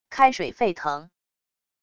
开水沸腾wav音频